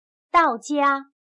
到家/dàojiā/Hogar